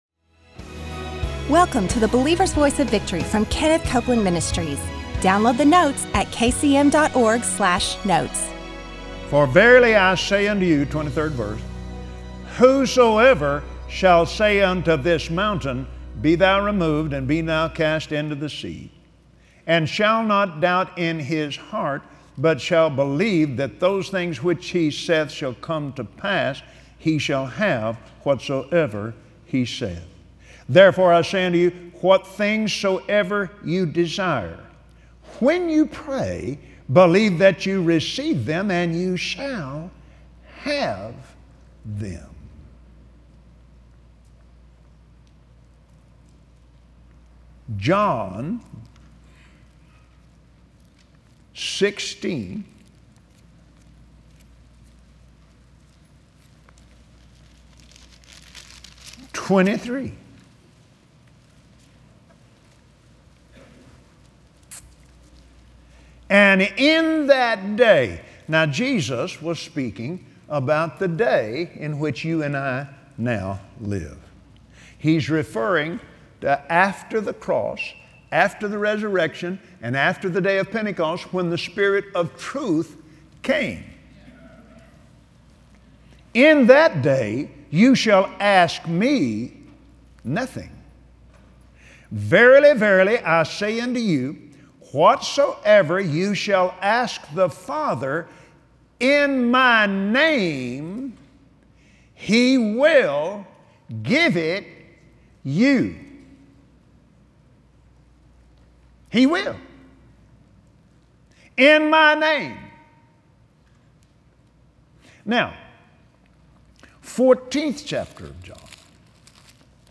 Today on the Believer’s Voice of Victory, Kenneth Copeland explains what your Heavenly Grant is and how to access it by faith, so you can to walk in your benefits of Heaven.